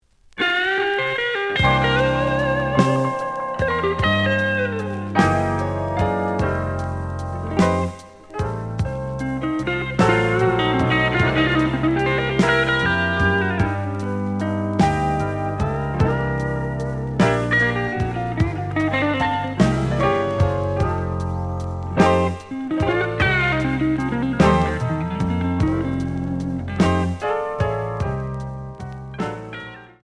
試聴は実際のレコードから録音しています。
●Genre: Blues